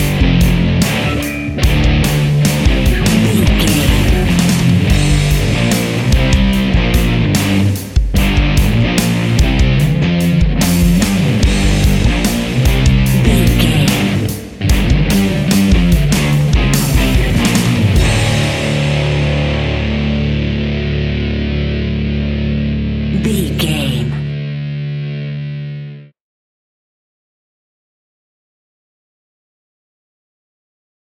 Powerful Rock Music Cue 15 Sec Mix.
Epic / Action
Fast paced
Aeolian/Minor
heavy metal
instrumentals
Rock Bass
heavy drums
distorted guitars
hammond organ